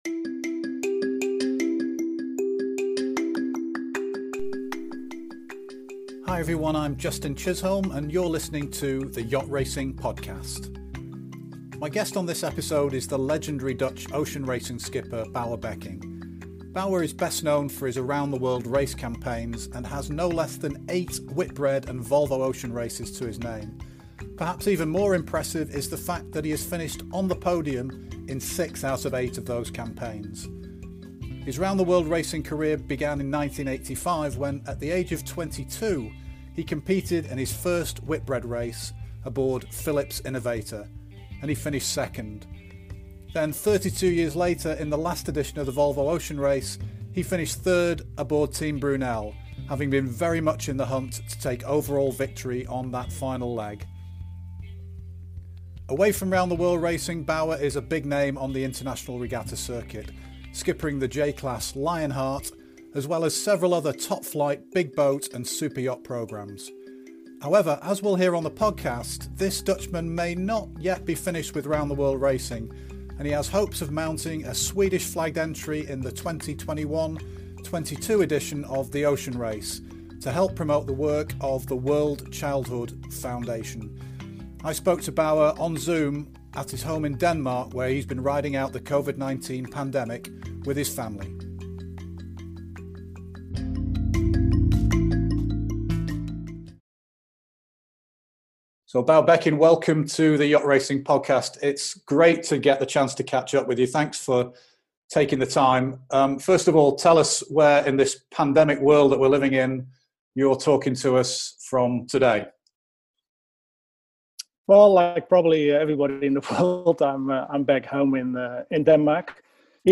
Our guest on the latest episode of The Yacht Racing Podcast is the legendary Dutch ocean racing skipper Bouwe Bekking. Bekking is best known as an around-the-world race campaigner and has eight Whitbread and Volvo Ocean Races to his name.